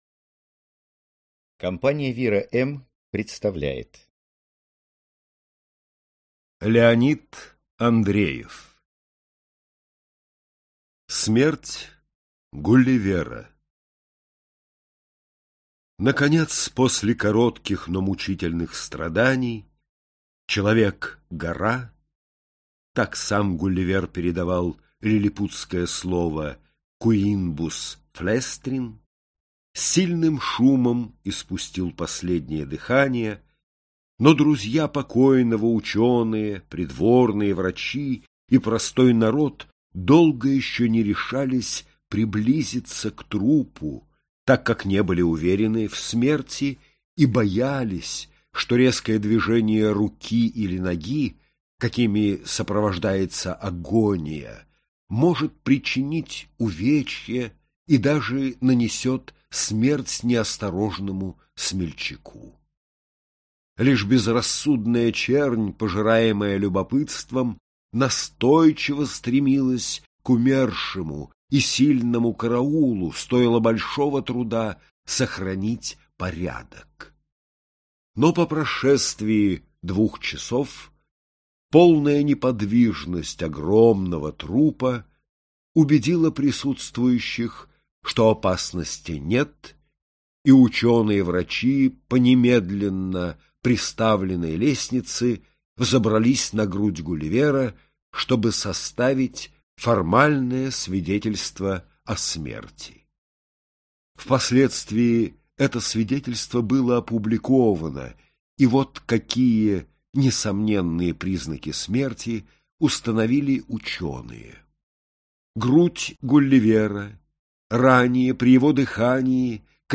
Аудиокнига Смерть Гулливера. Рассказы | Библиотека аудиокниг